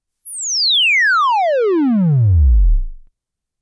落下音・はねる音
落下音2